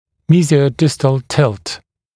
[ˌmiːzɪəu’dɪstl tɪlt][ˌми:зиоу’дистл тилт]мезиодистальный наклон